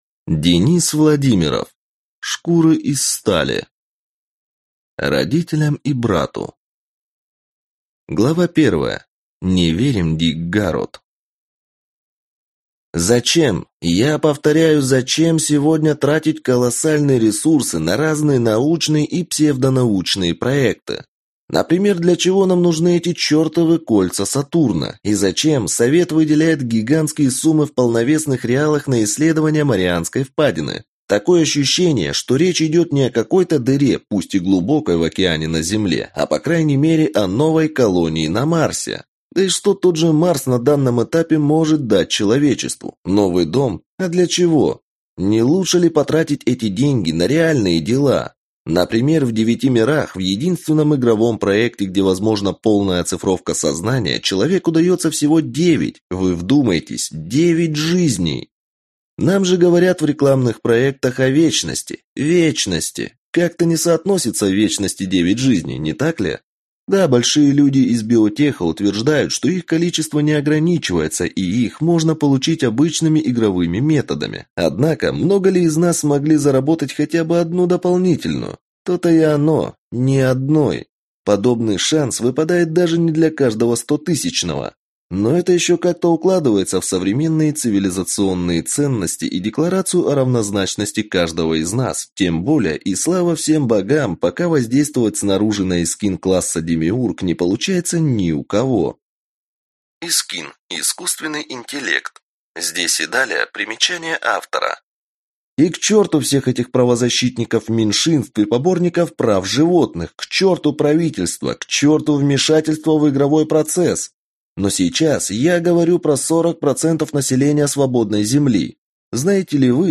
Aудиокнига Шкуры из стали